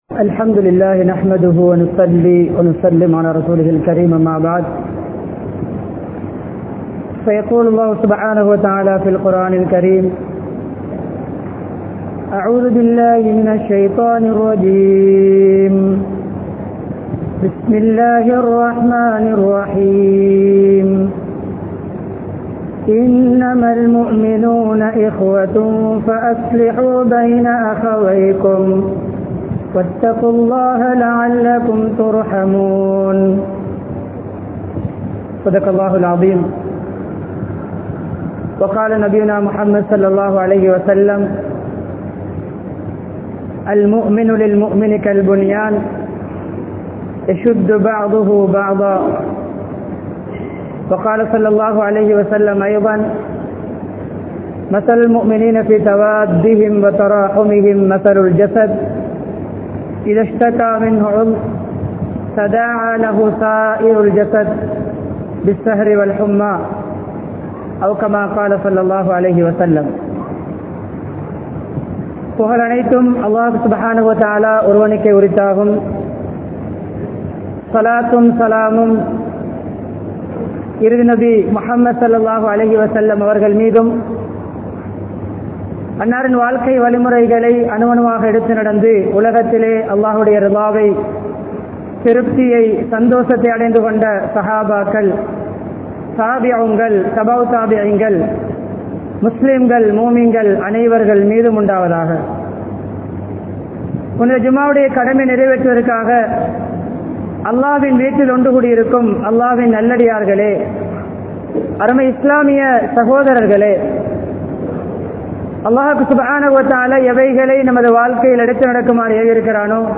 Islam Oru Saanthi Maarkam (இஸ்லாம் ஒரு சாந்தி மார்க்கம்) | Audio Bayans | All Ceylon Muslim Youth Community | Addalaichenai
Umbitchi Jumua Masjidh